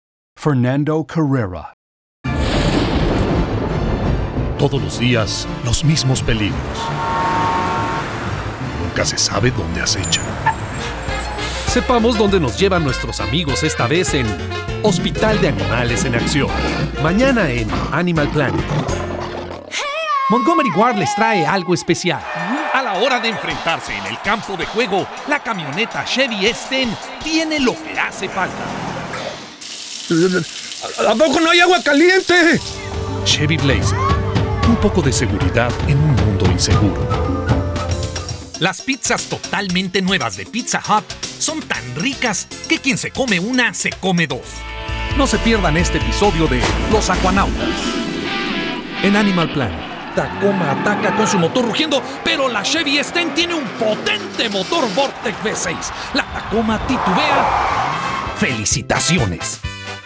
My Acting Demo Video click here to play video click here to download file My Voice Over Demo Reel Click here to listen to my VO Demo click here to download file Click to see my Television Host Demo Video